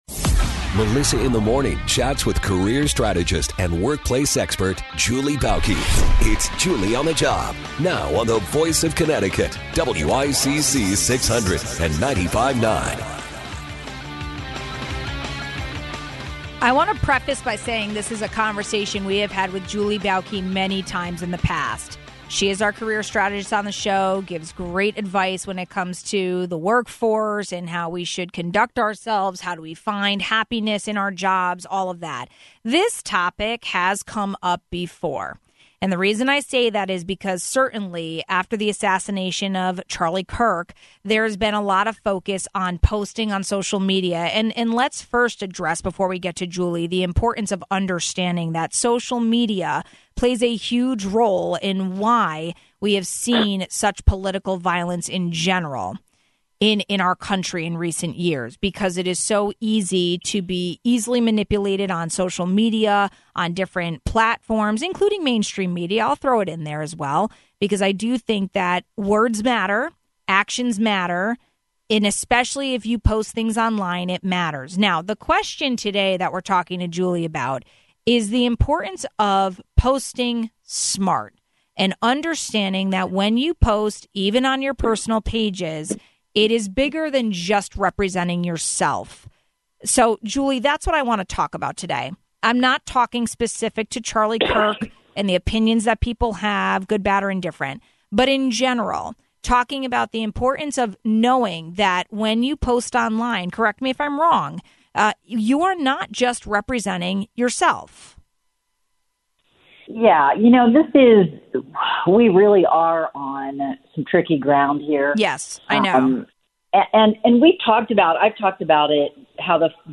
We spoke with career strategist